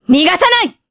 Priscilla-Vox_Skill3_jp.wav